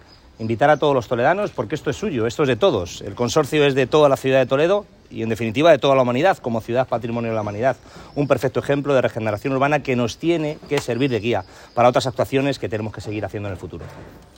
Cortes de voz: